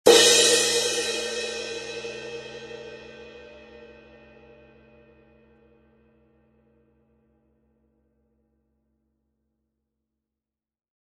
Zildjian 18" A Custom Projection Crash Cymbal